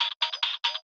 Hi Hat 06.wav